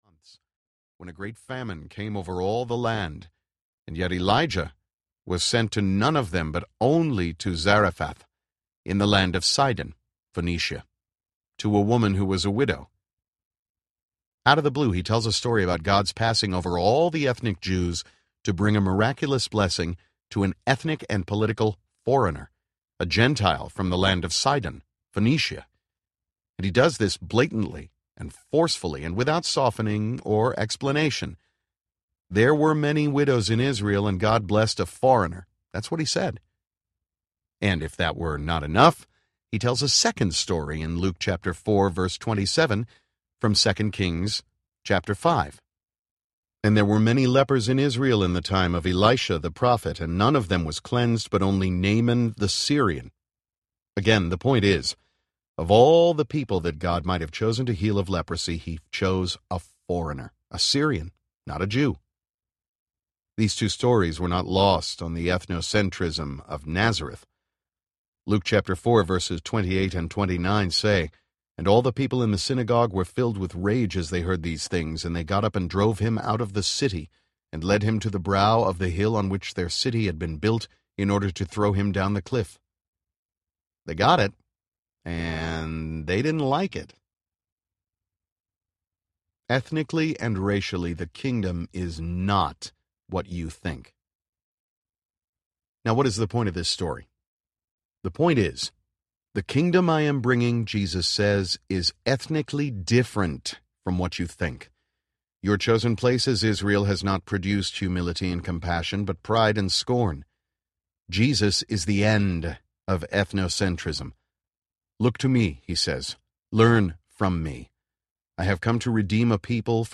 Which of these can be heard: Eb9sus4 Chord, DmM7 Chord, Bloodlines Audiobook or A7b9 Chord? Bloodlines Audiobook